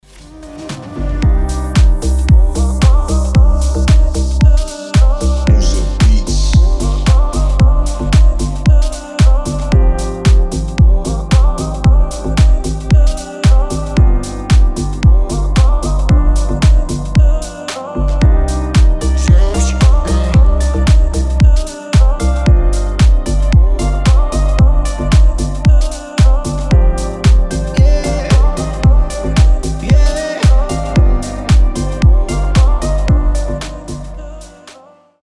deep house
мелодичные
релакс
пианино
расслабляющие
биты